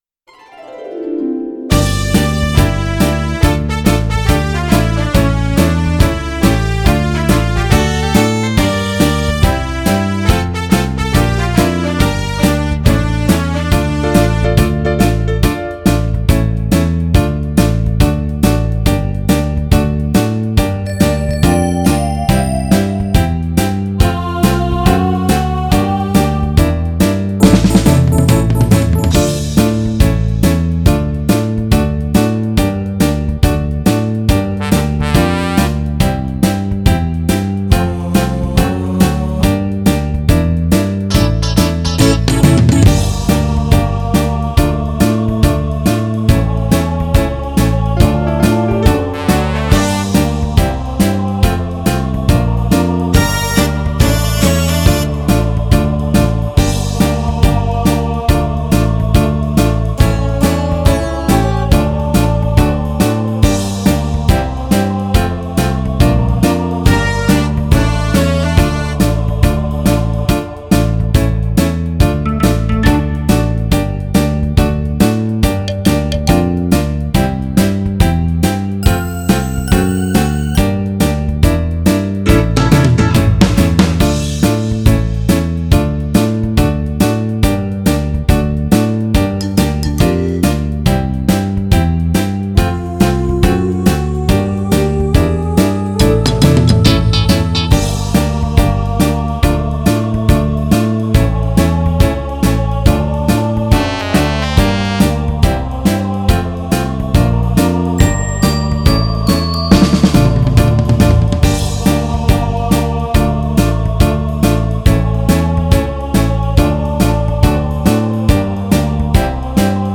Минус песни